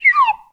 whistle_slide_down_03.wav